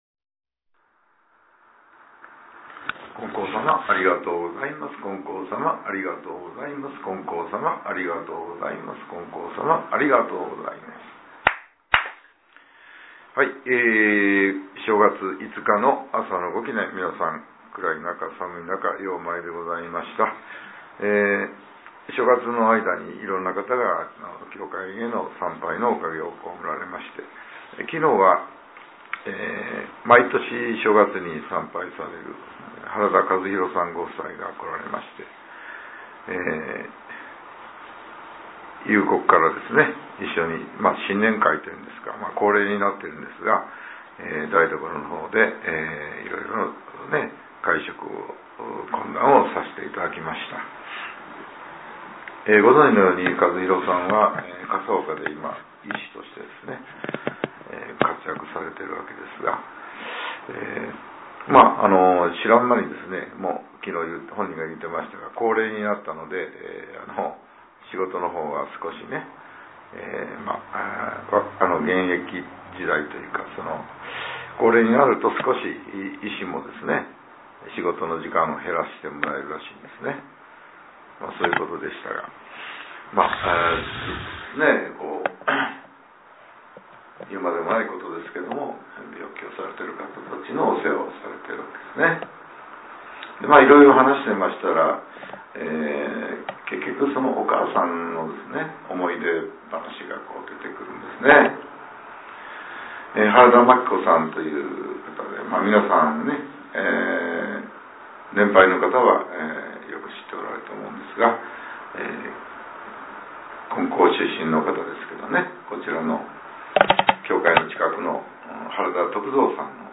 令和８年１月５日（朝）のお話が、音声ブログとして更新させれています。